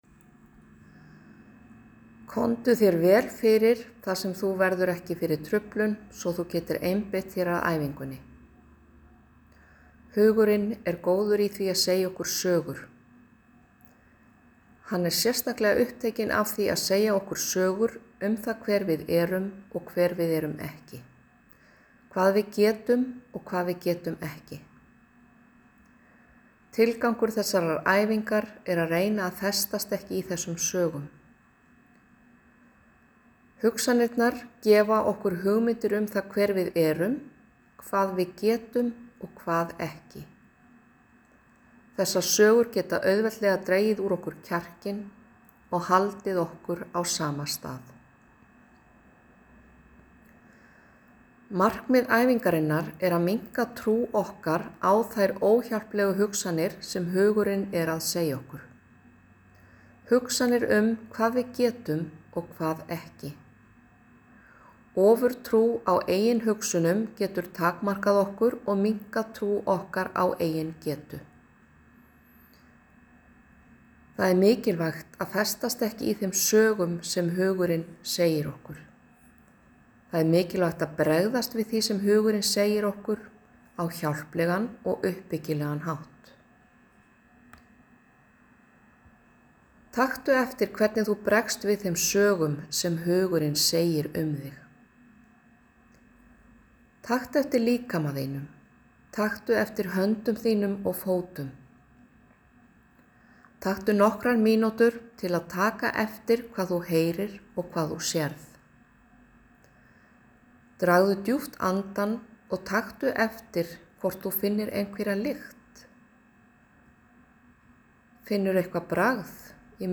ACT hugleiðsla.m4a